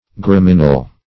gramineal - definition of gramineal - synonyms, pronunciation, spelling from Free Dictionary Search Result for " gramineal" : The Collaborative International Dictionary of English v.0.48: Gramineal \Gra*min"e*al\, a. Gramineous.
gramineal.mp3